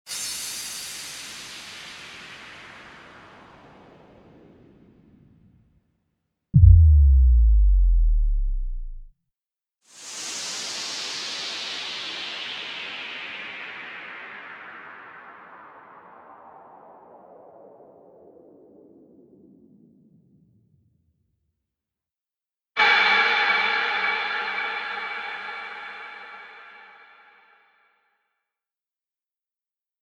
FX+Preview.mp3